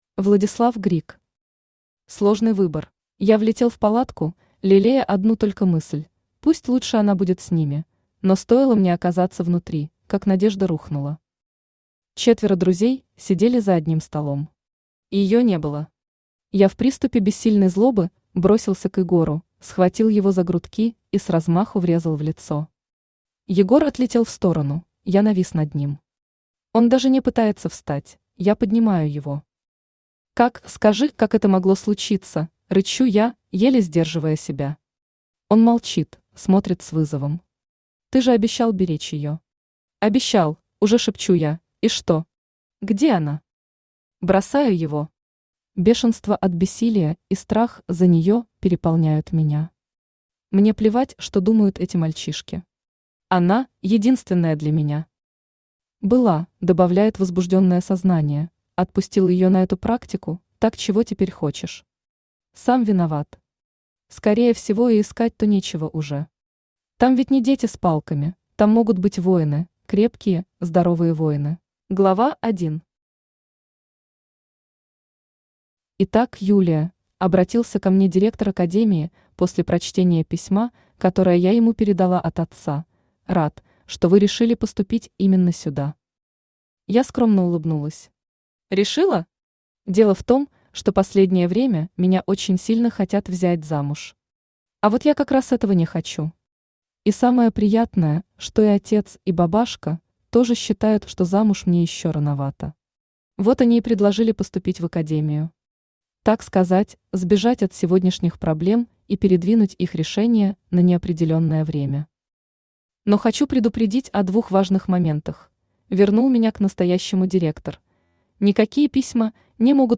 Аудиокнига Сложный выбор | Библиотека аудиокниг
Aудиокнига Сложный выбор Автор Владислав Григ Читает аудиокнигу Авточтец ЛитРес.